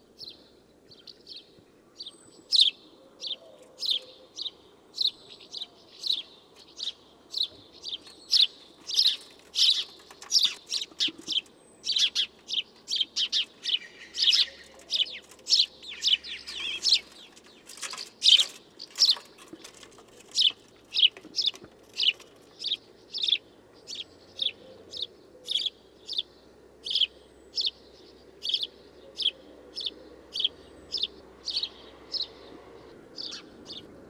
Gorrión – Universidad Católica de Temuco
Gorrion-passer-domesticus.wav